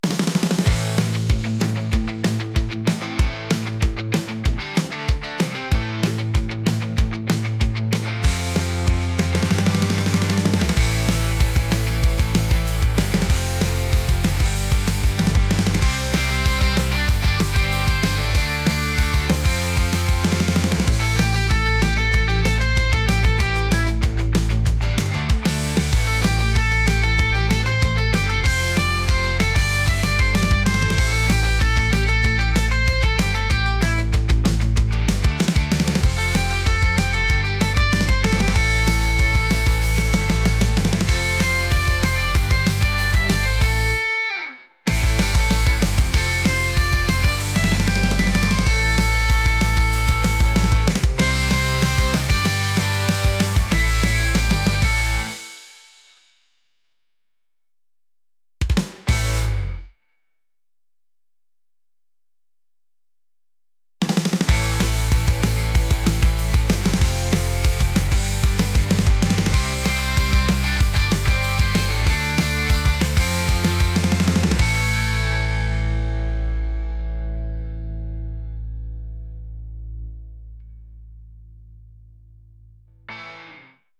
punk | rock